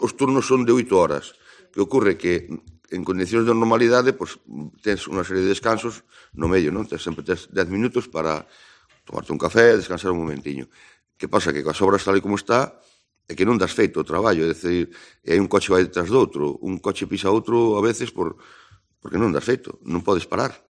en rueda de prensa